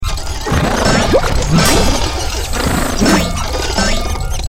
audio: Converted sound effects